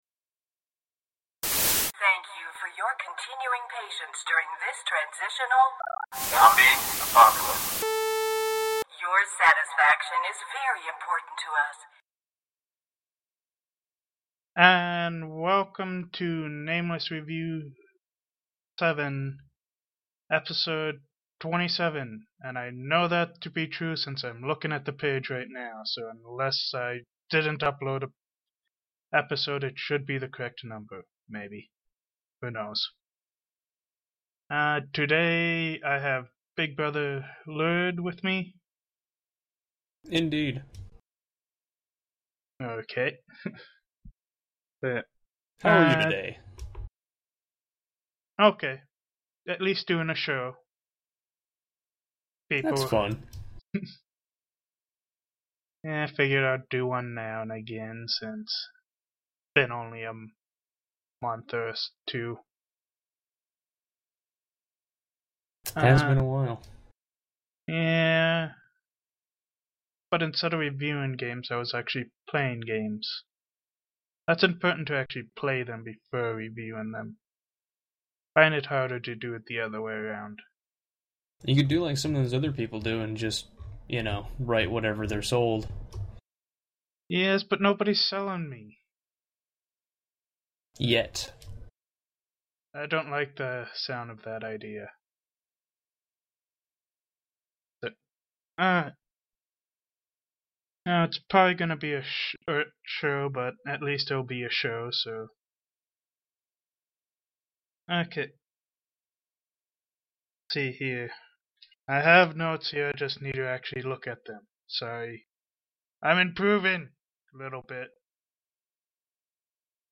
The we had a few problems, but do to laziness I did not clean up the episode.